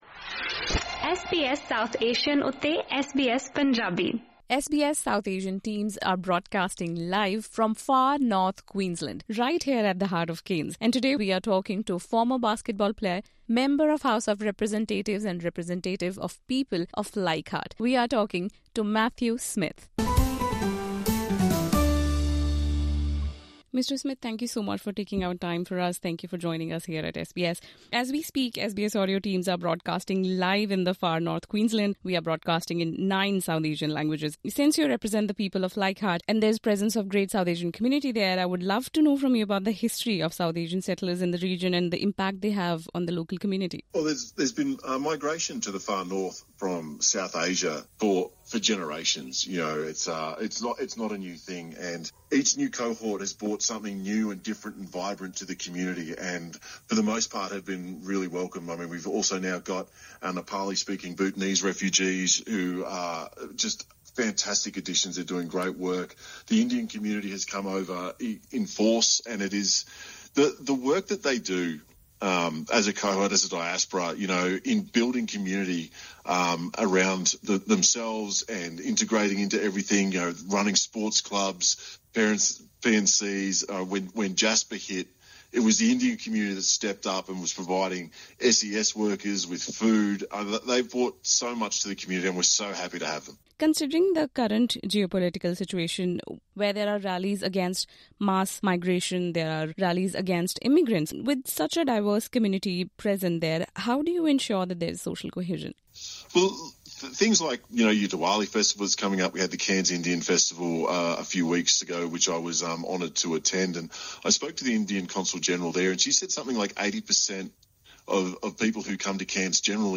Matt Smith, Member for Leichhardt and former Cairns Taipans basketball player, said that South Asian communities have been settling in Far North Queensland for generations, with each cohort bringing something “new and vibrant” to the region. Speaking to SBS Punjabi, he said that multiculturalism is an integral part of the Far Northern story and shared his plans for celebrating the upcoming Diwali festival with the diverse community. Listen to an interaction with him as the SBS South Asian teams broadcast live from Cairns via this podcast....